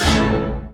68_21_stabhit-A.wav